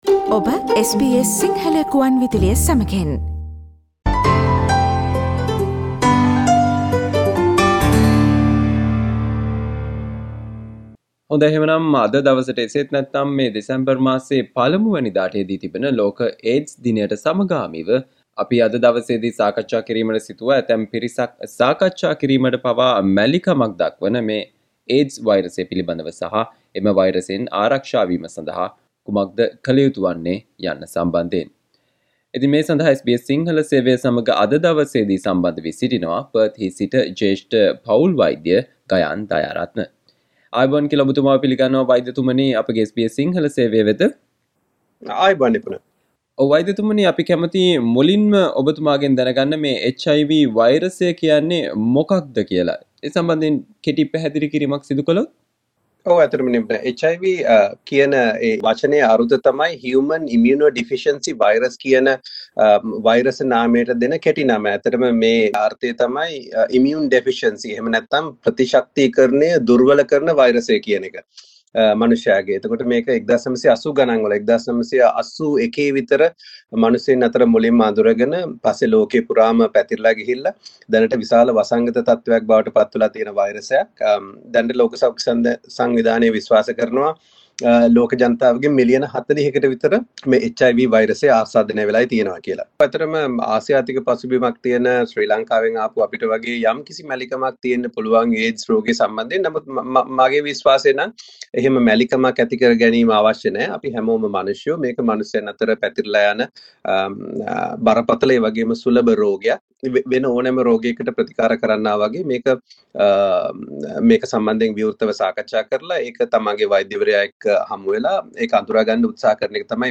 SBS Sinhala discussion